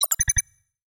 Holographic UI Sounds 72.wav